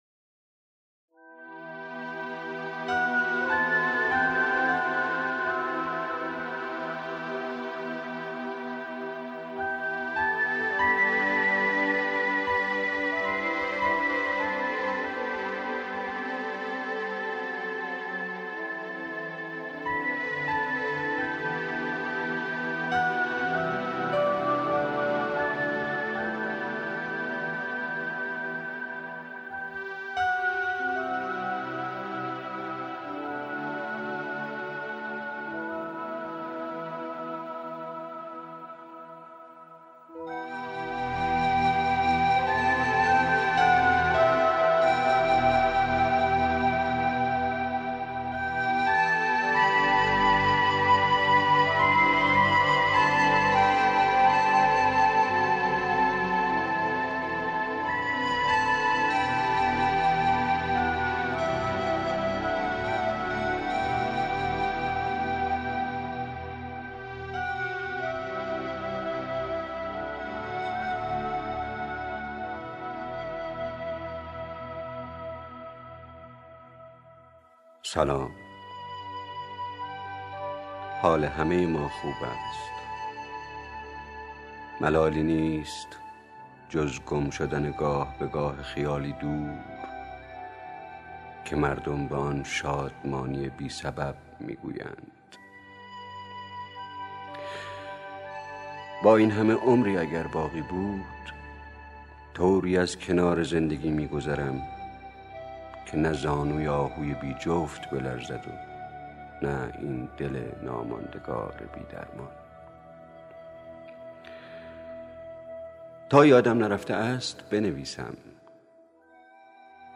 دریافت فایلدانلود شعرخوانی با صدای خسرو شکیبایی
صدا: خسرو شکیبایی
by: Vangelis- West Across The Ocean Sea